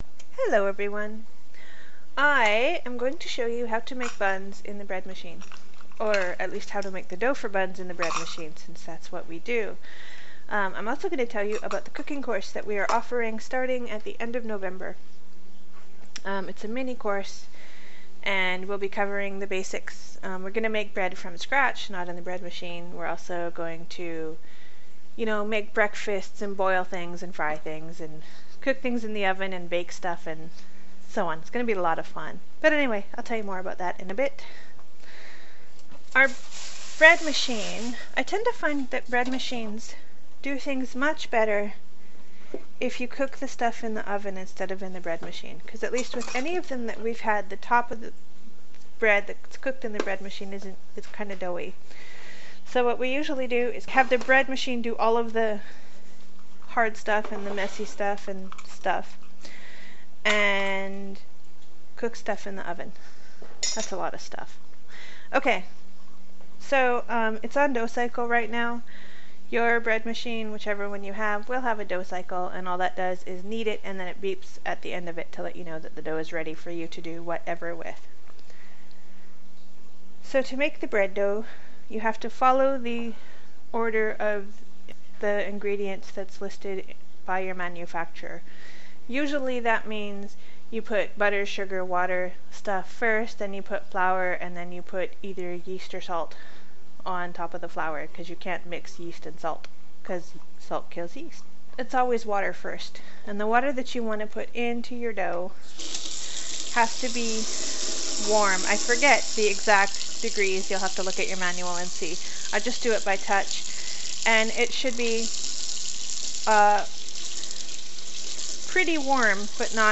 Want to know the kind of thing our Cooking 101 course will teach? Tune in as I bake a batch of buns in the bread machine.